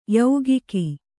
♪ yaugiki